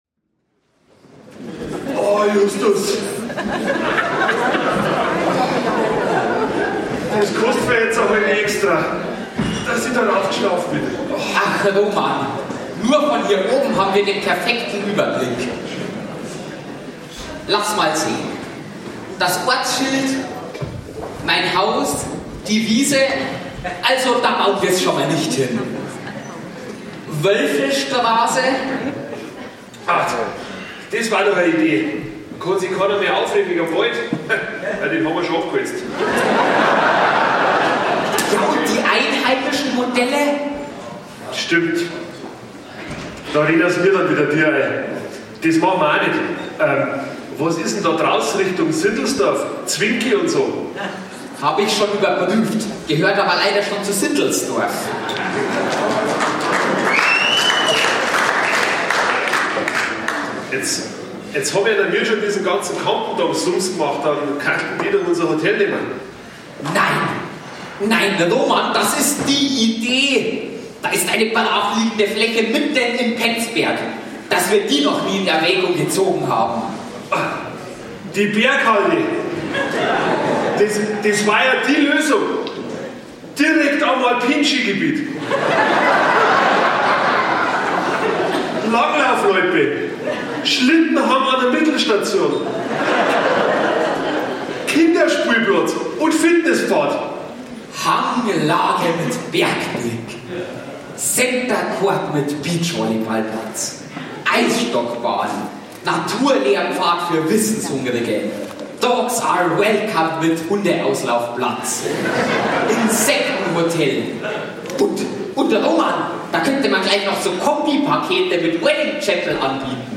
Starkbieranstich
Singspiel – „Der Hotelstandort“ – Die Verwaltung auf der Standortsuche (Ausschnitt): 3:46 min.